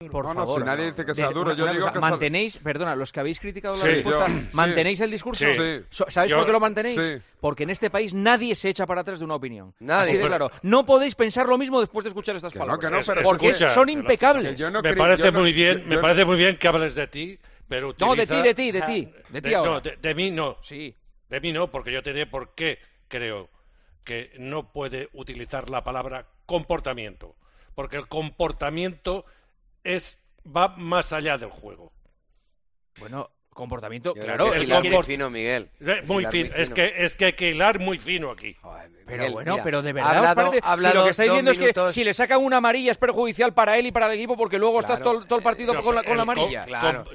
Después de escuchar las palabras de Luis de la Fuente, Juanma Castaño preguntó si seguían manteniendo los tertulianos de 'El Partidazo de COPE' lo que opinan sobre la situación de Gavi también tras conocer la información de 'Relevo' y emitió una opinión que puedes escuchar aquí.